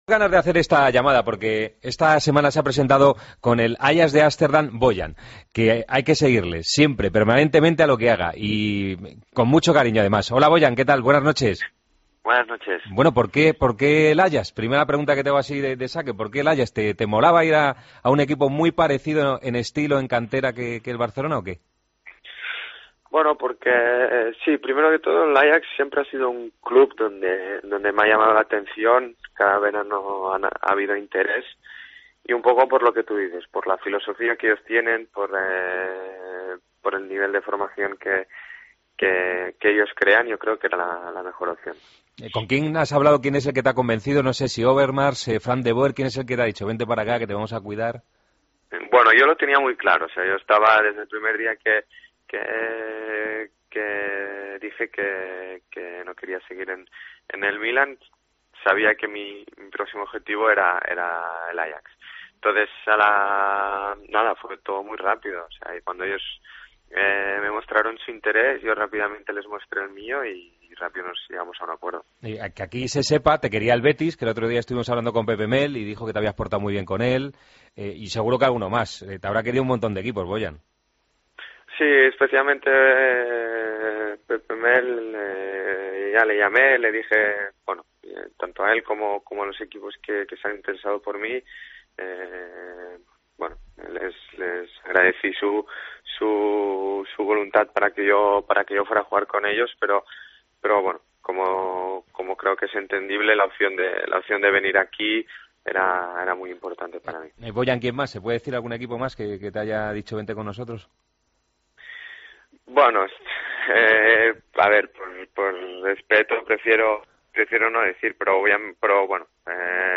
Entrevista a Bojan, en El Partido de las 12